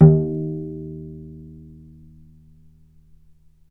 vc_pz-D#2-mf.AIF